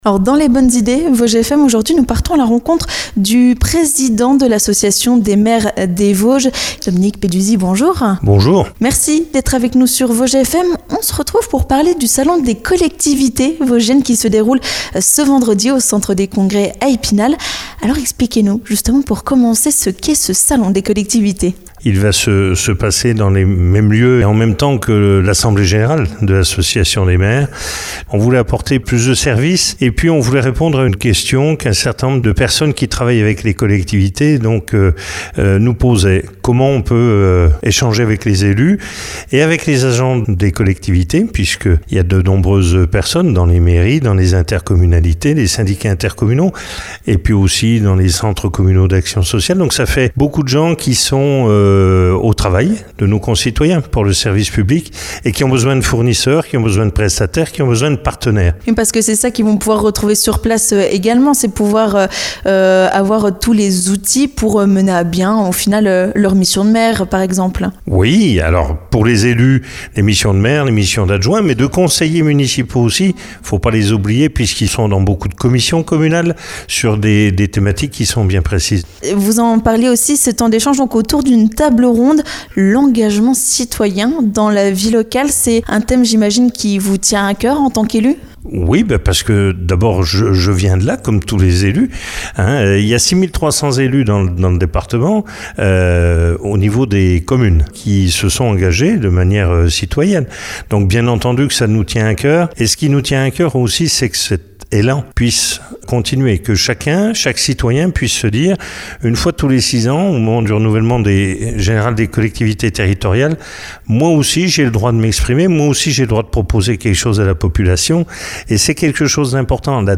Le Président de l’Association des Maires des Vosges, Dominique Peduzzi nous en dit plus sur cette journée.